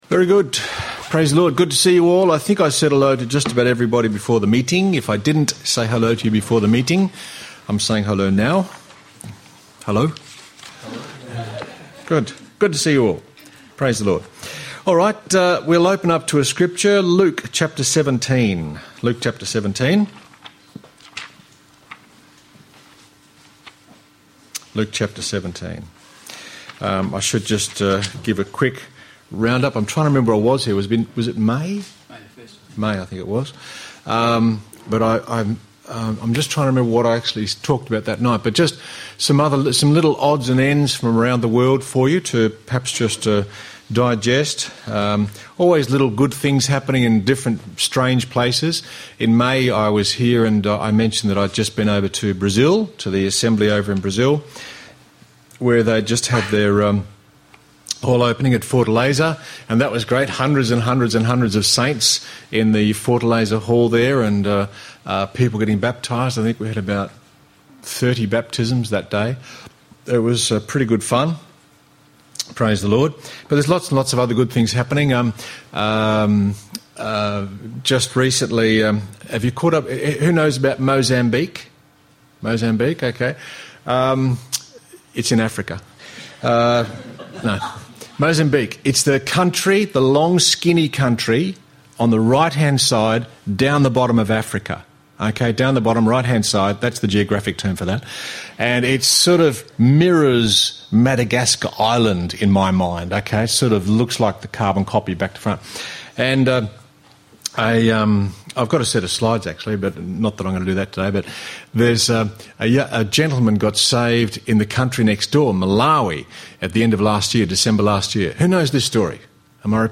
A classic talk from the TRF Talk Radio archives